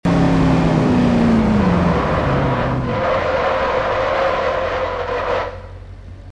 throttle_off.wav